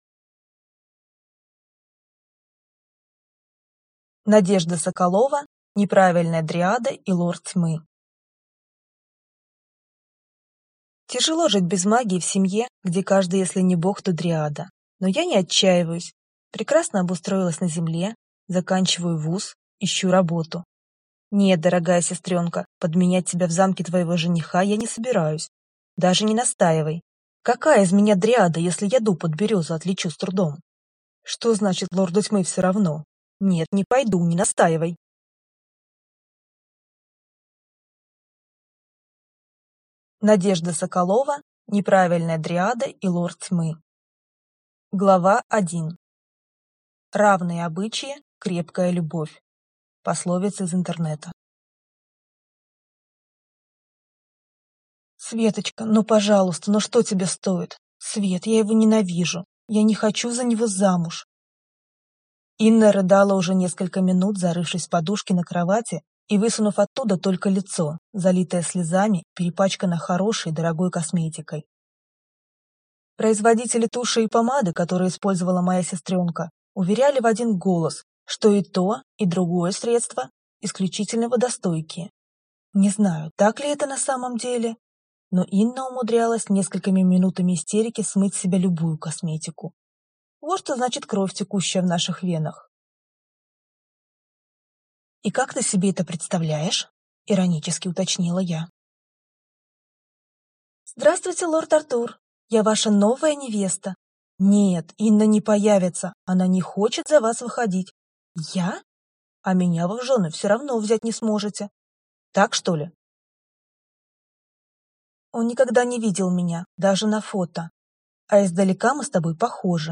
Аудиокнига Неправильная дриада и Лорд тьмы | Библиотека аудиокниг
Прослушать и бесплатно скачать фрагмент аудиокниги